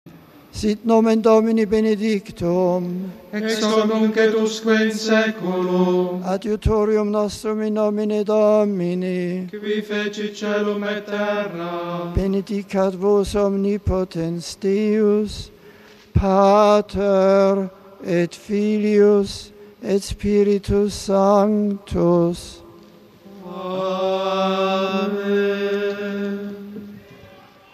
The Mass over, Pope Benedict appeared at the window of studio of his studio to pray the noontime ‘Angelus’ prayer with a holiday crowd gathered in St. Peter’s Square. After addressing them in Italian the Pope prayed the 'Angelus' and gave his blessing.